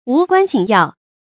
無關緊要 注音： ㄨˊ ㄍㄨㄢ ㄐㄧㄣˇ ㄧㄠˋ 讀音讀法： 意思解釋： 緊要：急切的，重要的。